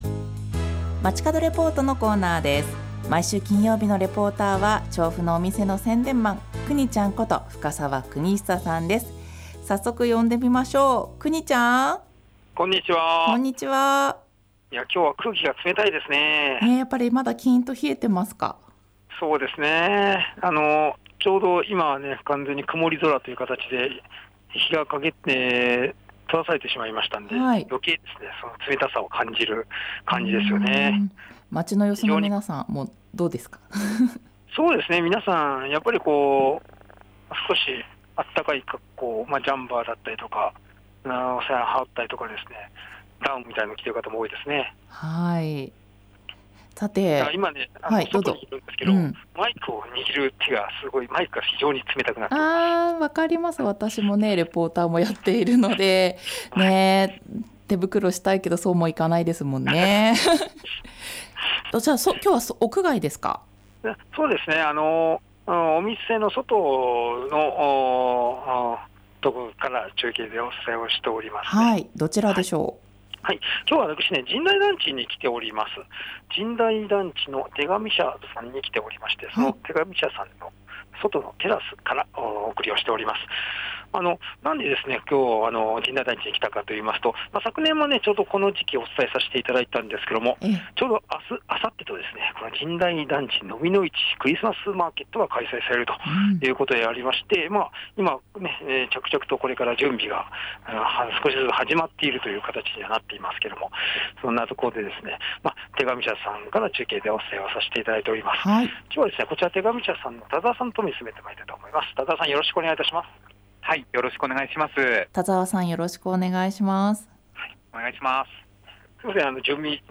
午後のカフェテラス 街角レポート